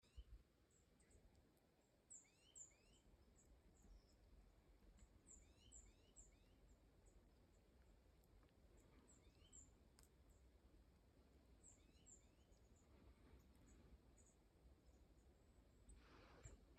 Nuthatch, Sitta europaea
StatusVoice, calls heard